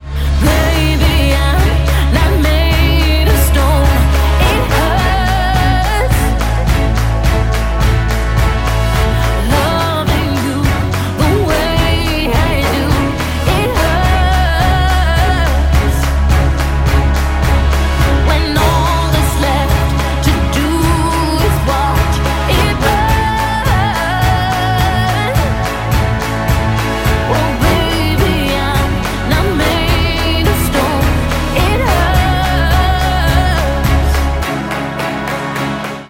• Качество: 192, Stereo
женский вокал
грустные
печальные
романтические